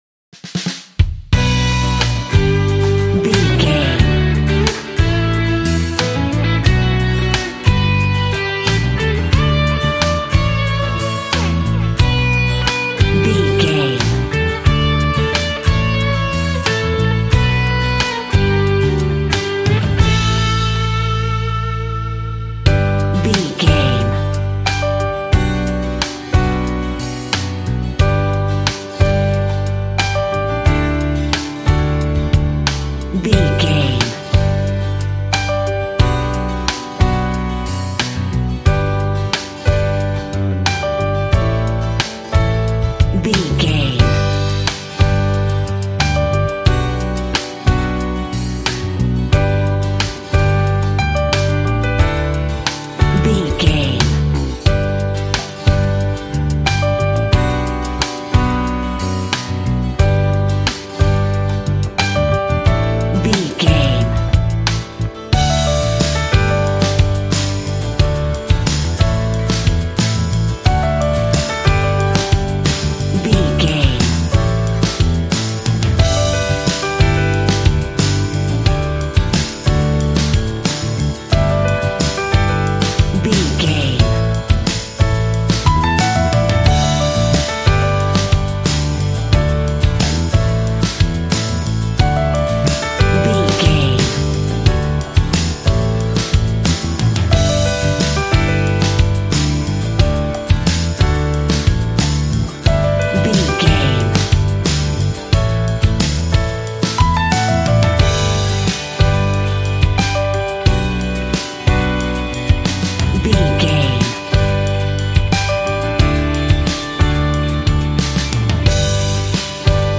Ionian/Major
romantic
happy
bass guitar
drums